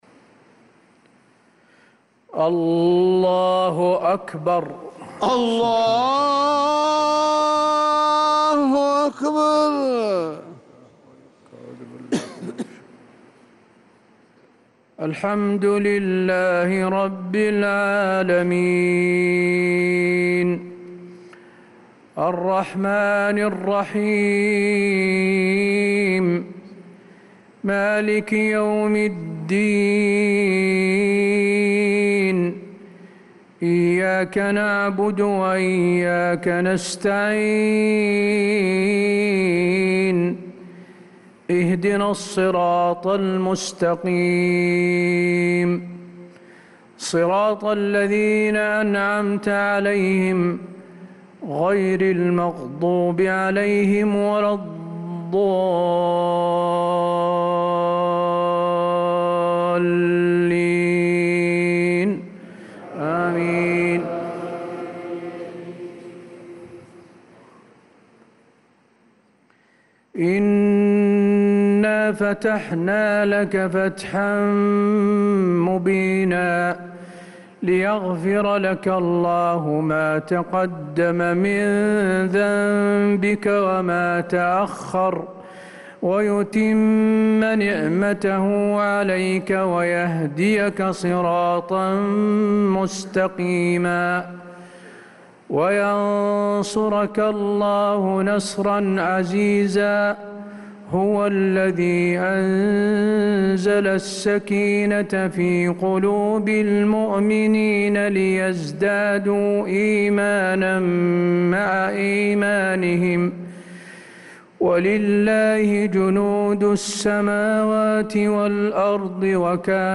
صلاة العشاء للقارئ حسين آل الشيخ 29 محرم 1446 هـ